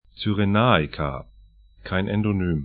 Kyrenaika   tsyre'na:ika